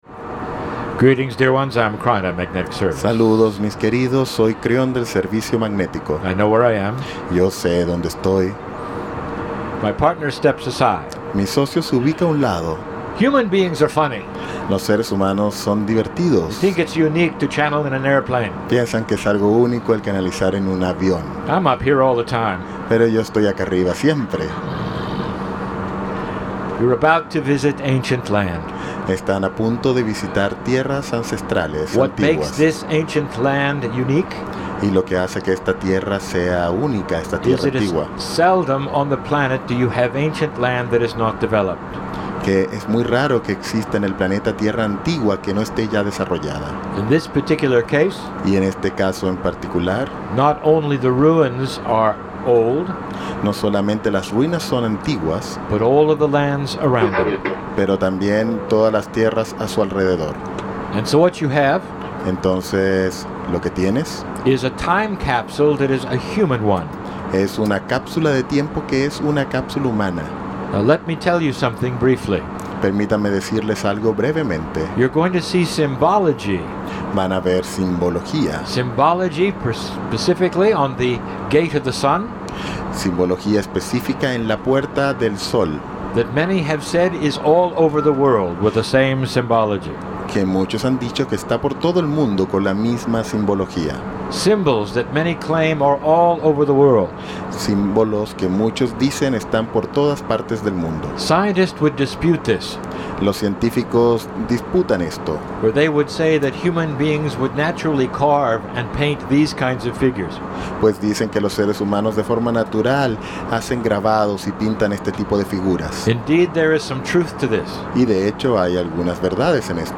KRYON CHANNELLING
Airplane channelling! - Nov 9
Airplane.mp3